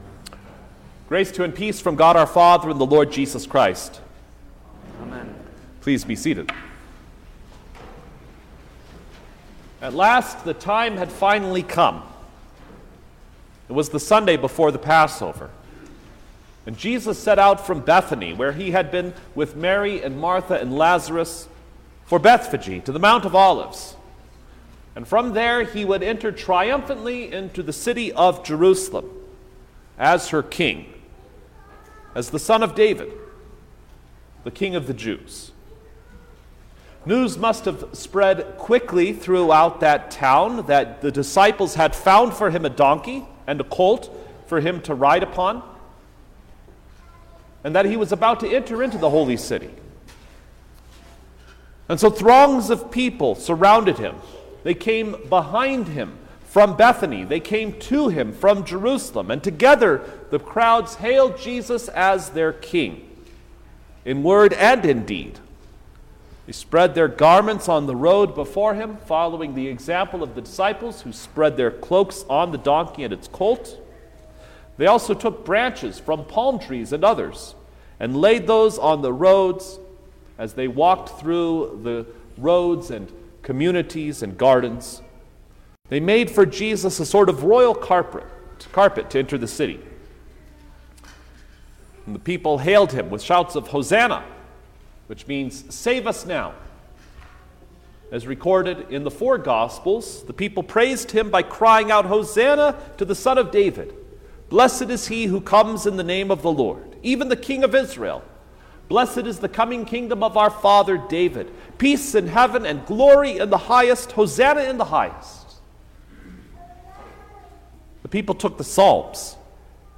December-3_2023_First-Sunday-in-Advent_Sermon-Stereo.mp3